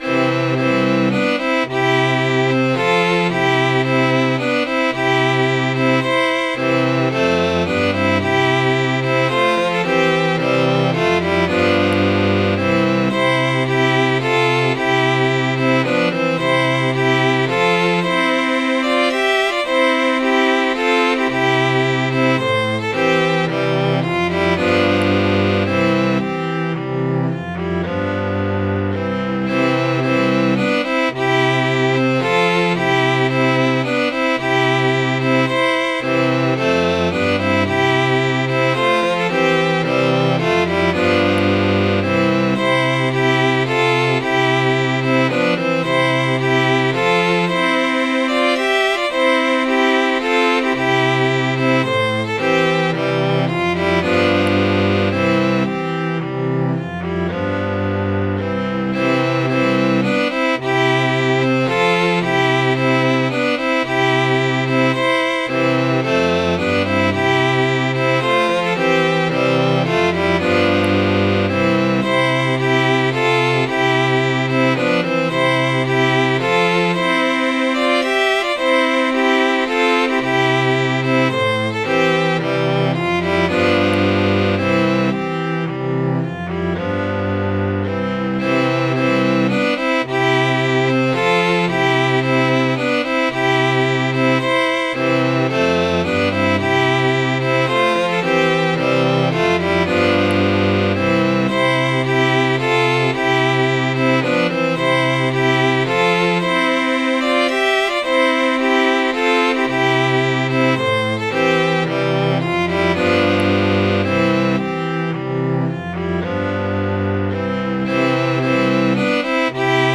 donflora.mid.ogg